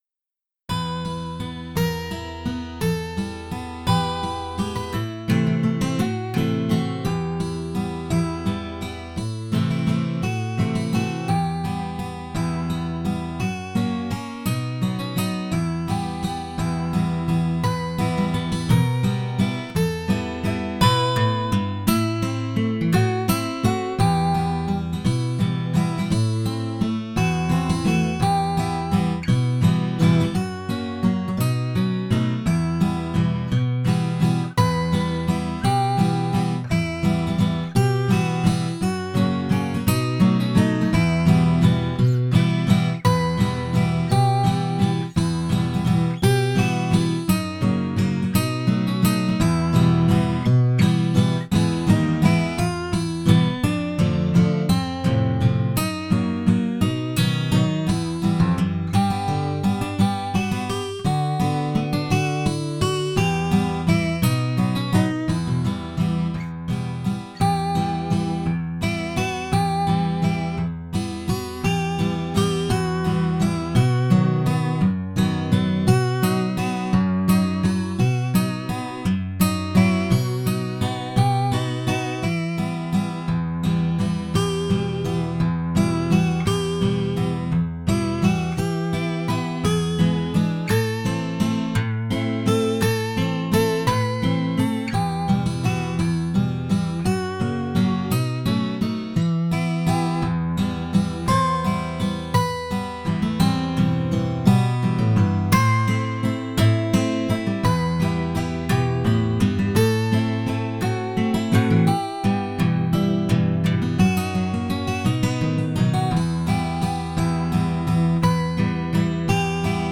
Очень понравился гитарный вариант, выложенный в одном из постов на Завалинке.